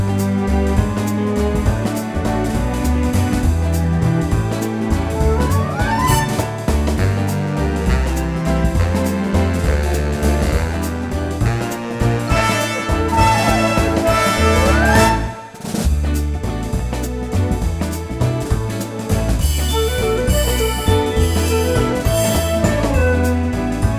no Backing Vocals Crooners 2:30 Buy £1.50